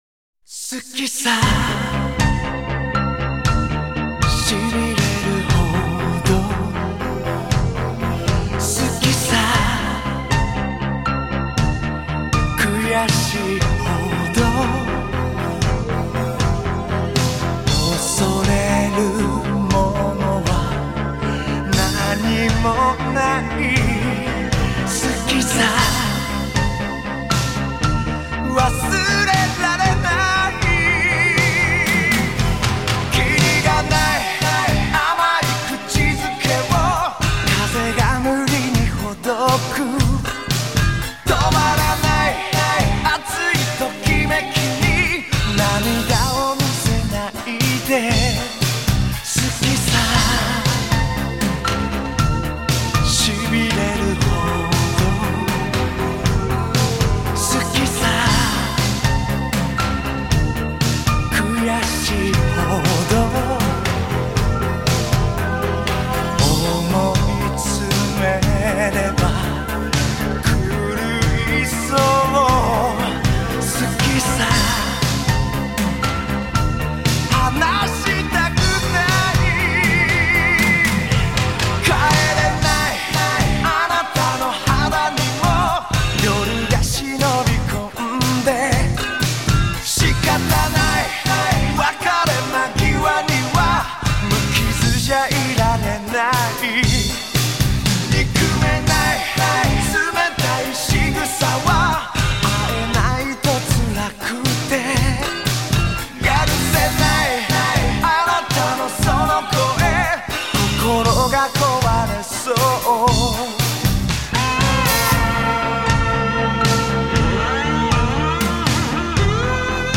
Sigla di apertura